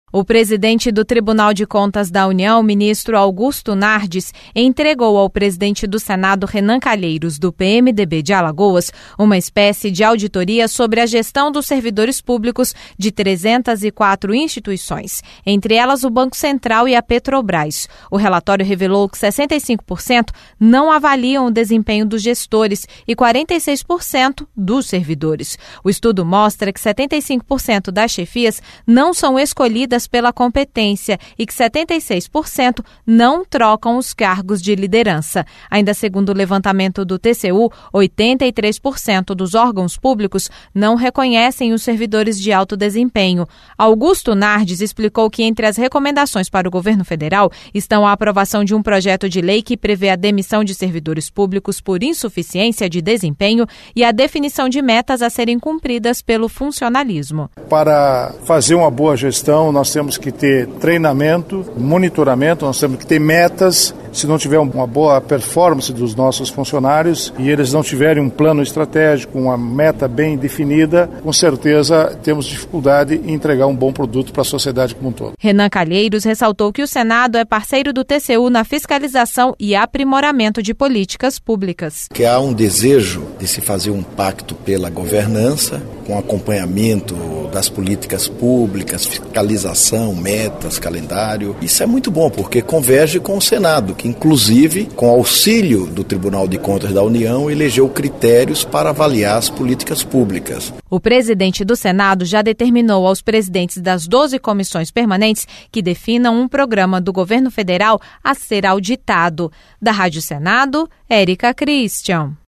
Rádio Senado - Ao Vivo